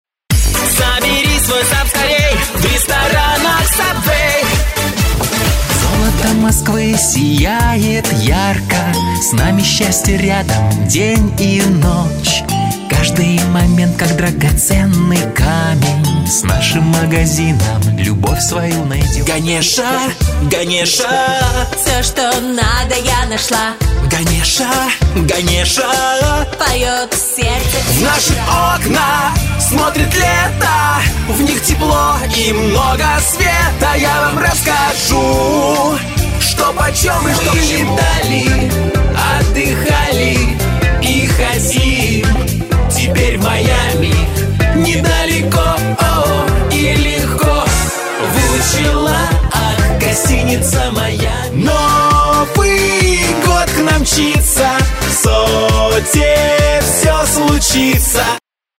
Тракт: микрофон Neumann TLM 103 • предусилитель Long Voice Master • интерфейс RME Babyface Pro • акустическая тон-кабина IzoCab